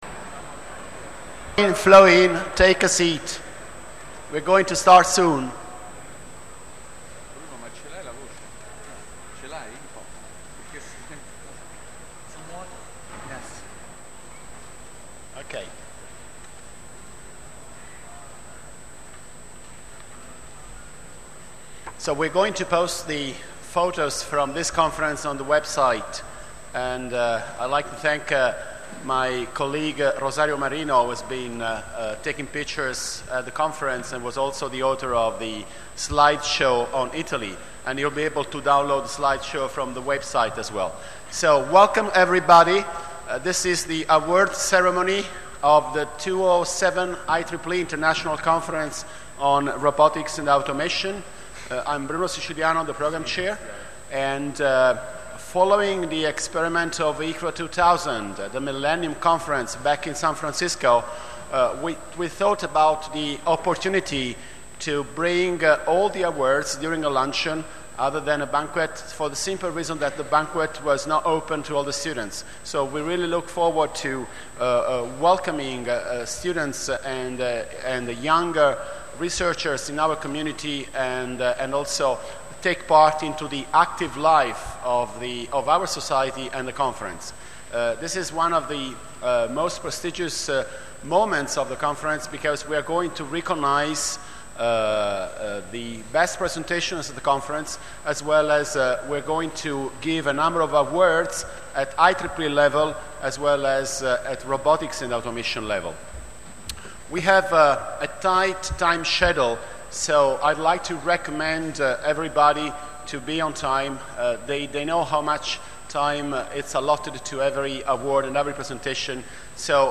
The audio of the ICRA'07 Awards Ceremony of Friday 13 April is available here .